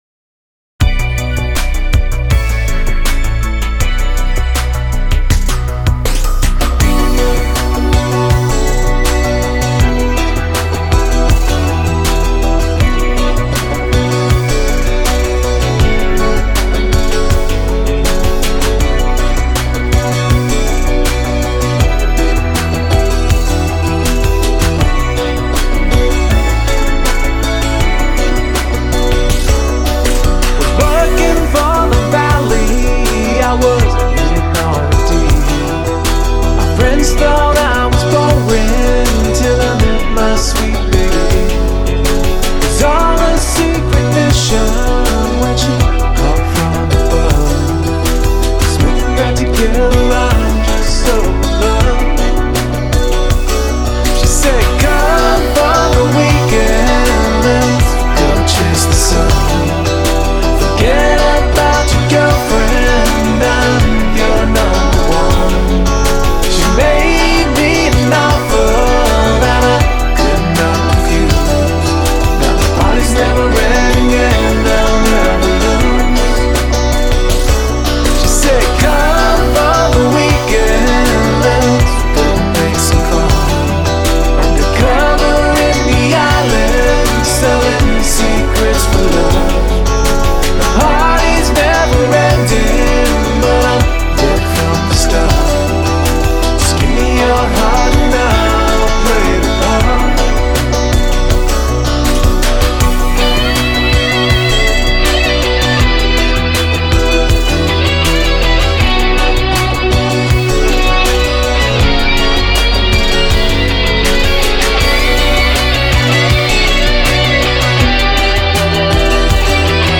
"In The Islands" - Funk/Reggae/Electro
Dreamy tropical blend of genres for your ears.